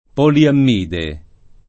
poliammide